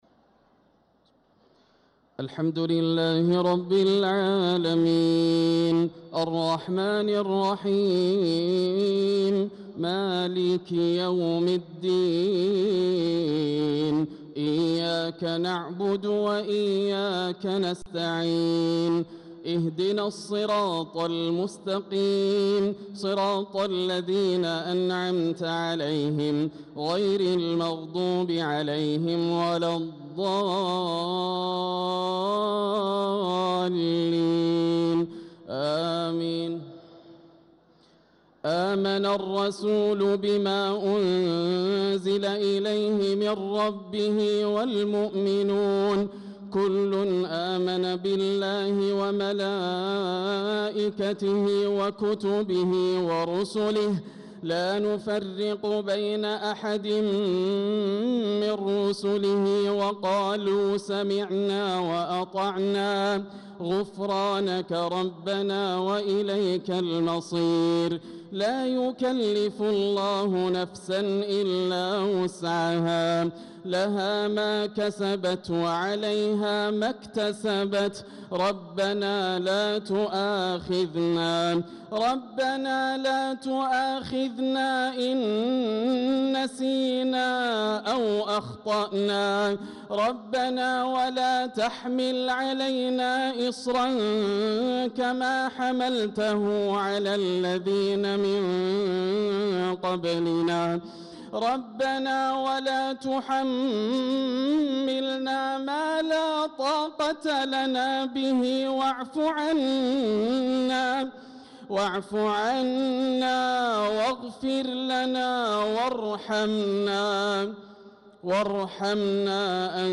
صلاة العشاء للقارئ ياسر الدوسري 12 ذو الحجة 1445 هـ